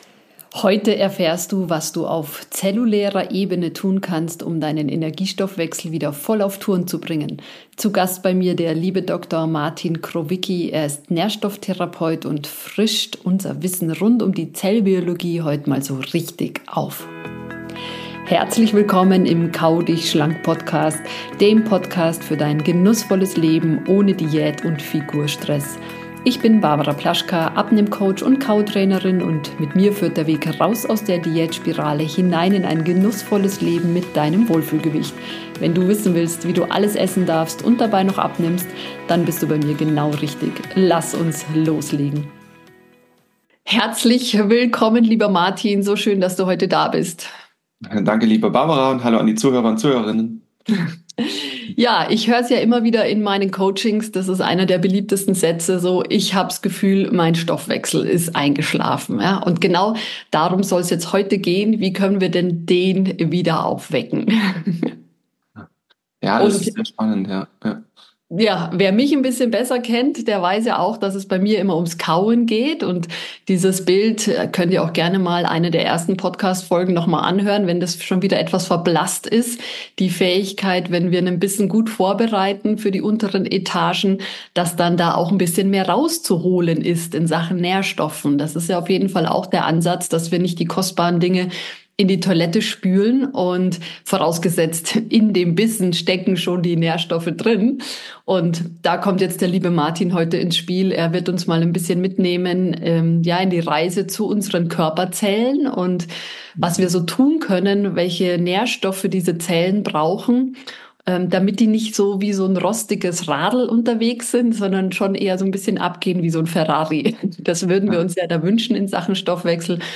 So stärkst du deine Mitochondrien - Im Interview